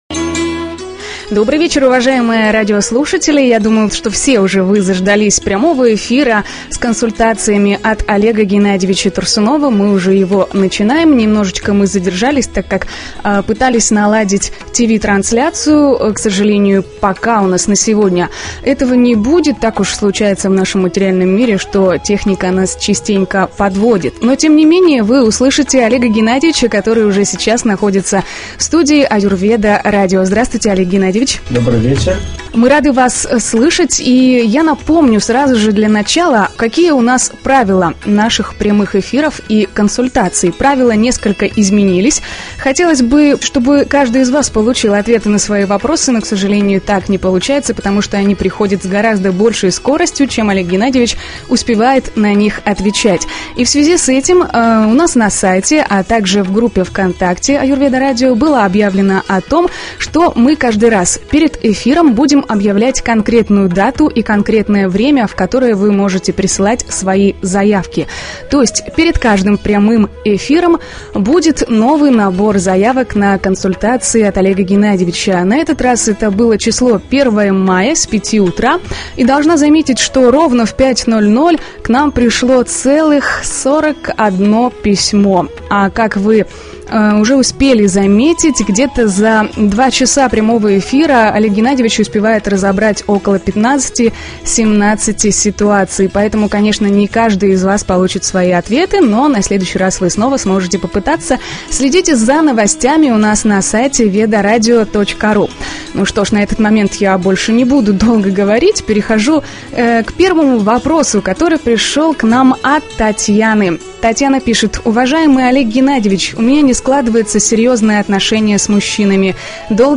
Консультации
в эфире Веда-радио